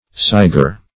sigher - definition of sigher - synonyms, pronunciation, spelling from Free Dictionary Search Result for " sigher" : The Collaborative International Dictionary of English v.0.48: Sigher \Sigh"er\, n. One who sighs.